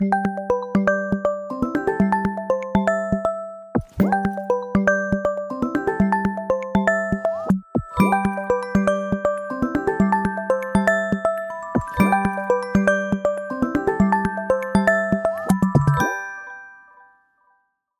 Tono de llamada